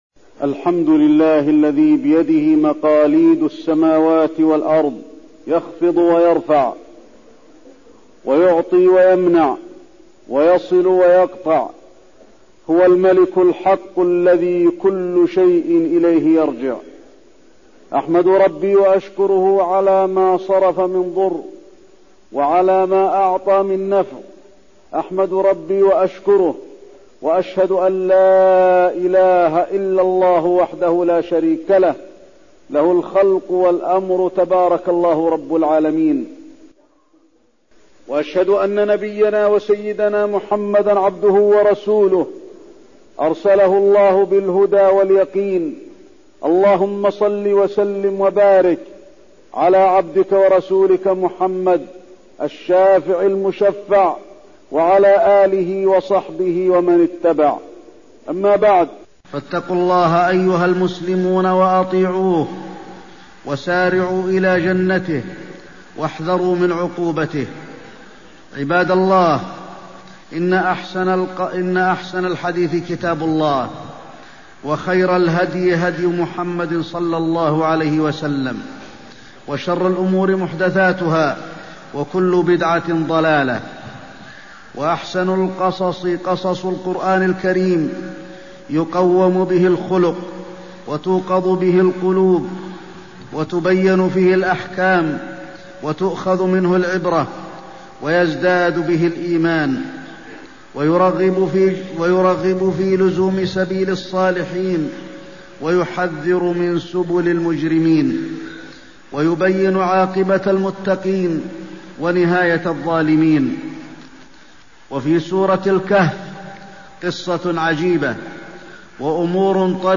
تاريخ النشر ٩ شوال ١٤١٨ هـ المكان: المسجد النبوي الشيخ: فضيلة الشيخ د. علي بن عبدالرحمن الحذيفي فضيلة الشيخ د. علي بن عبدالرحمن الحذيفي قصة الرجلين في سورة الكهف